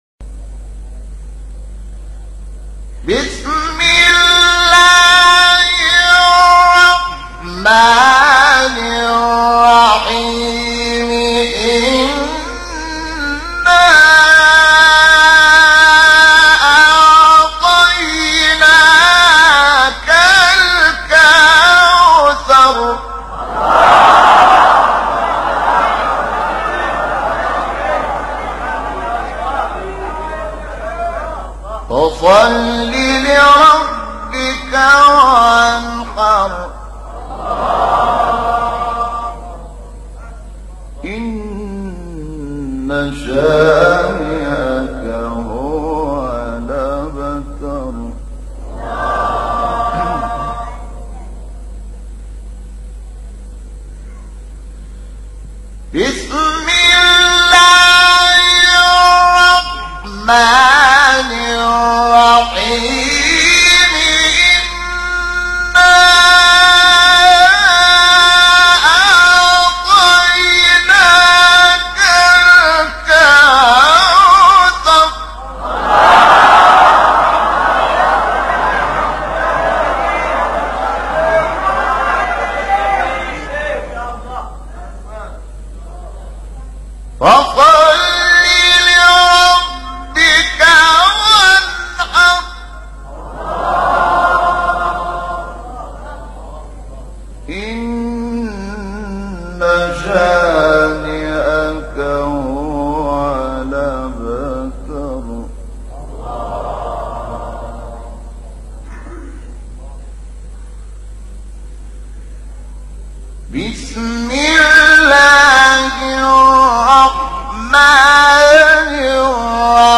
Audio | Rezitation aus der Sure Kauthar von „Shahat Mohammad Anwar“
Teheran (IQNA)- Hören Sie die Rezitation aus der Sure Kauthar des berühmten ägyptischen Koran-Rezitators; Diese Rezitation dauert 2 Minuten und 47 Sekunden.
Stichworte: der heilige Koran ، Sure Kauthar ، Rezitation ، Shahat Mohammad Anwar